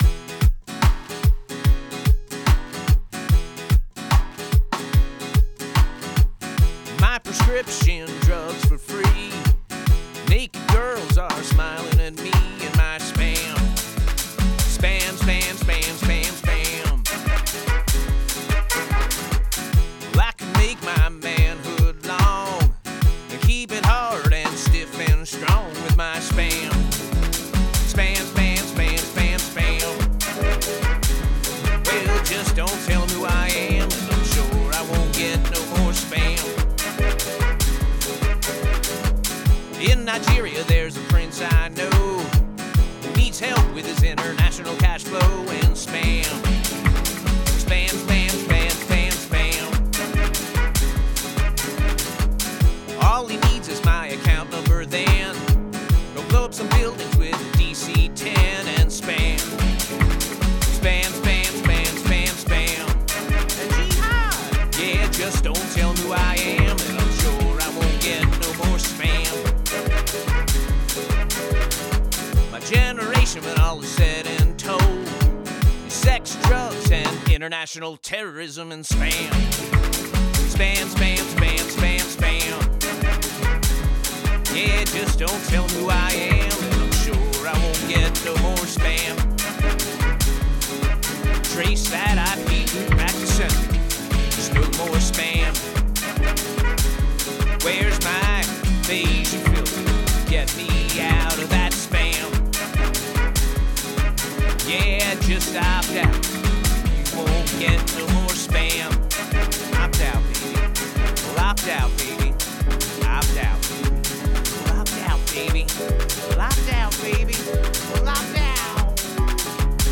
The first time I recorded this I abused it: Never even wrote the words down, and recorded vocals and guitar together. This time I went in dry with my Focusrite, and then ruined it with loops.